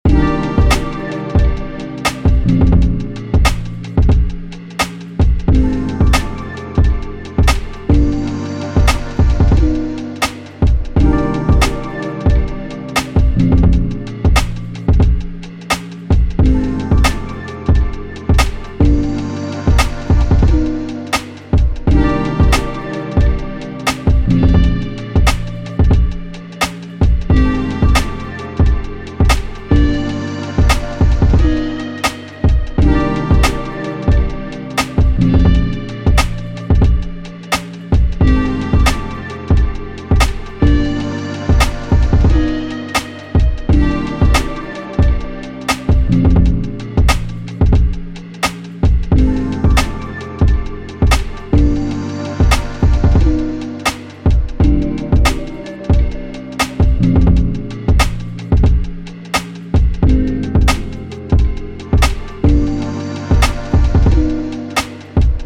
Hip Hop, 90s
Cm